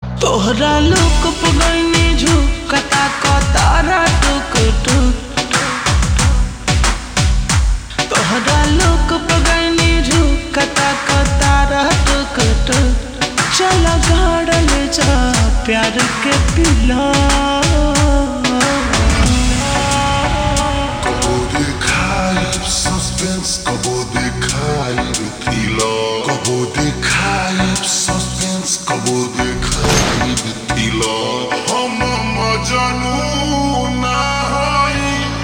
Bhojpuri Songs
Slow Reverb Version
• Simple and Lofi sound
• High-quality audio
• Crisp and clear sound